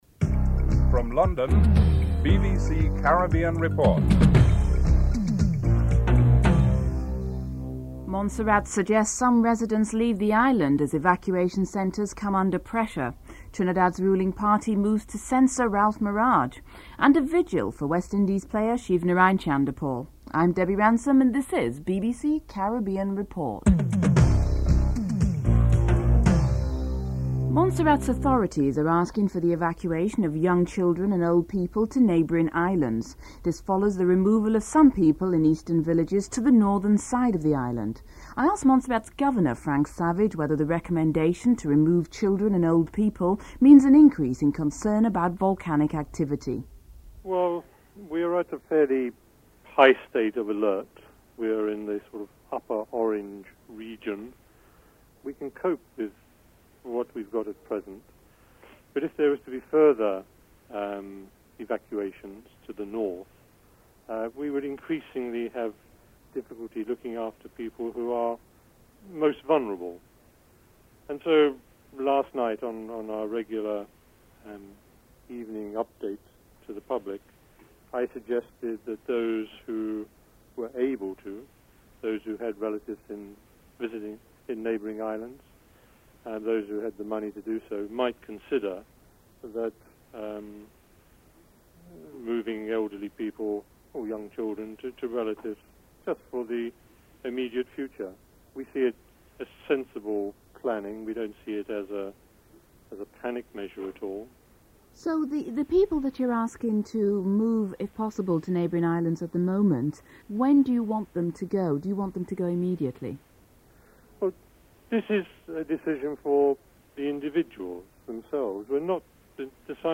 In this report, Montserrat authorities request the evacuation of young children and old people to neighbouring islands. Governor Frank Savage comments on the recommendation.